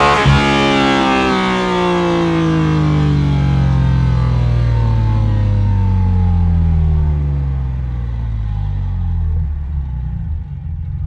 rr3-assets/files/.depot/audio/Vehicles/f6_01/f6_01_Decel.wav
f6_01_Decel.wav